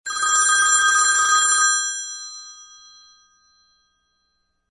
Категория : Стандартные